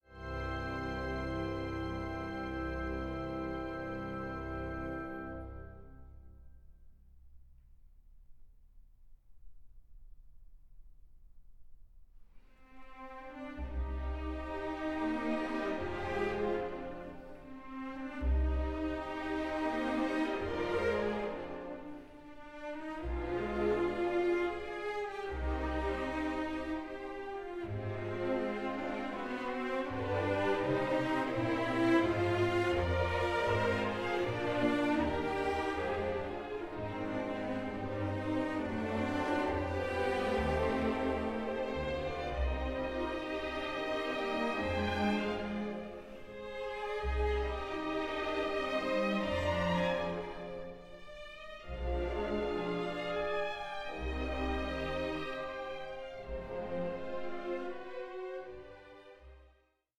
Symphony No. 2 in D Major, Op. 73